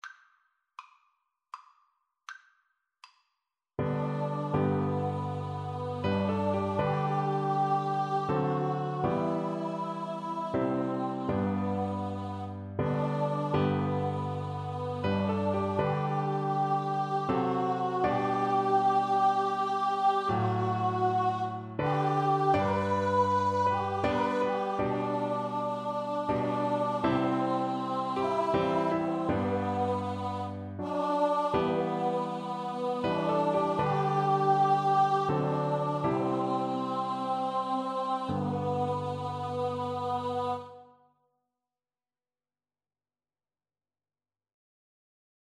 Free Sheet music for Vocal Duet
Amazing Grace is a Christian hymn with words written by the English poet and clergyman John Newton (1725–1807), published in 1779.
3/4 (View more 3/4 Music)
G major (Sounding Pitch) (View more G major Music for Vocal Duet )
Andante
Traditional (View more Traditional Vocal Duet Music)